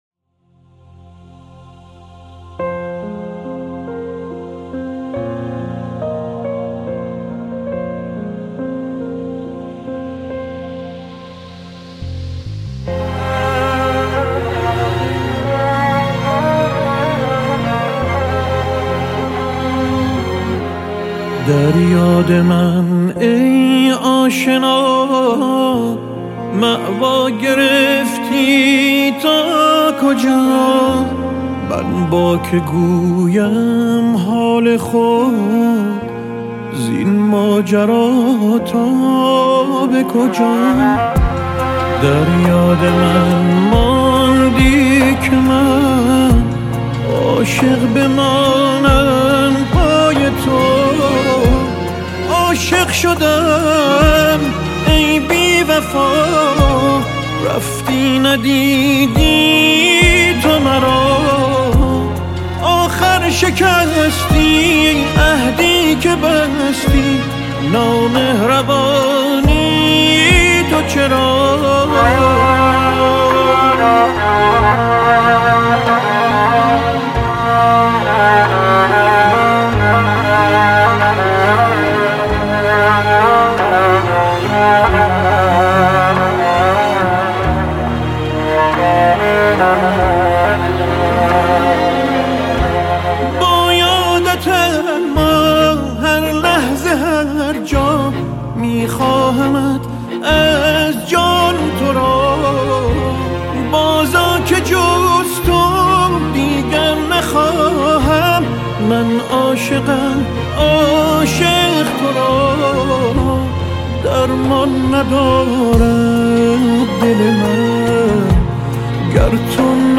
خوانندهٔ موسیقی سنتی است.
عاشقانه